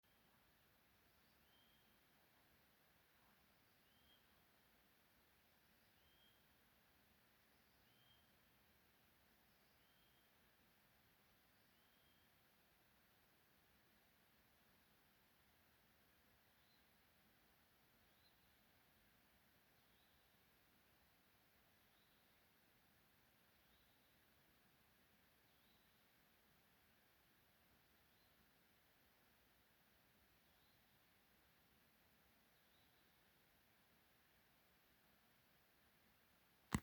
Krūmu ķauķis, Acrocephalus dumetorum
Administratīvā teritorijaDaugavpils novads
StatussDzied ligzdošanai piemērotā biotopā (D)